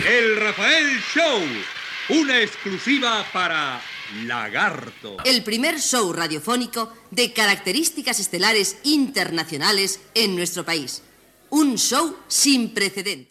Indicatiu del programa amb publicitat.
Entreteniment